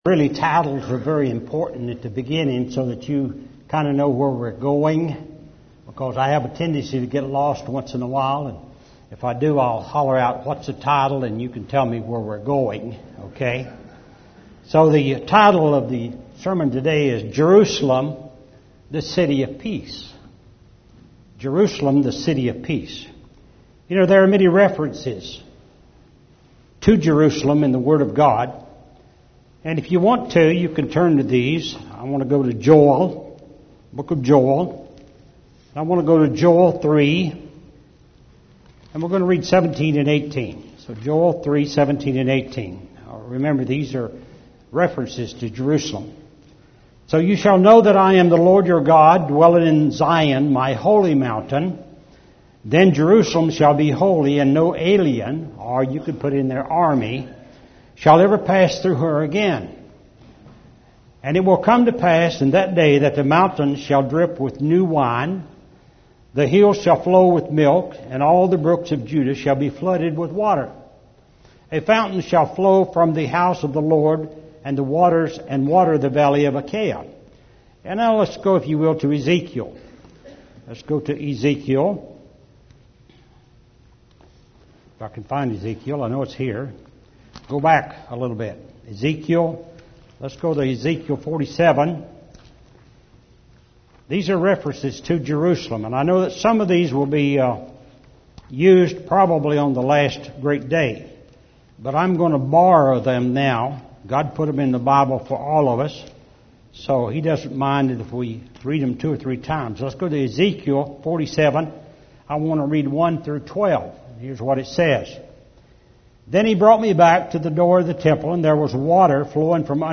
This sermon was given at the New Braunfels, Texas 2012 Feast site.